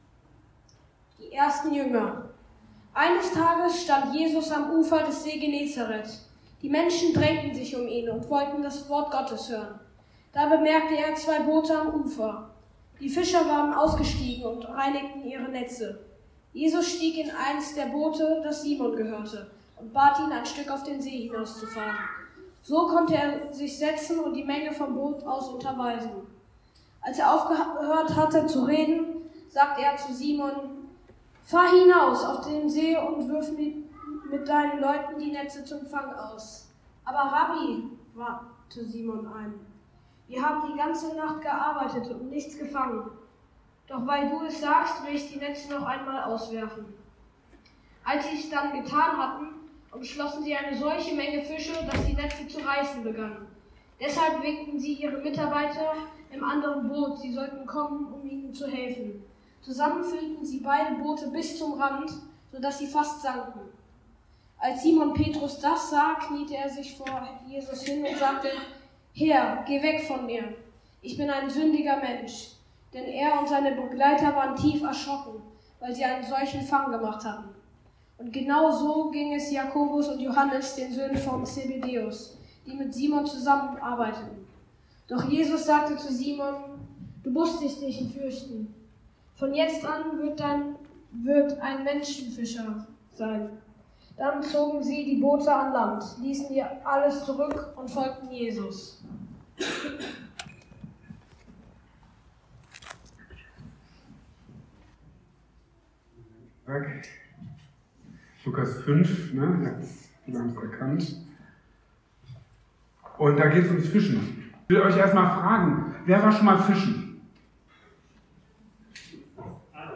Mehr als Fisch! | Marburger Predigten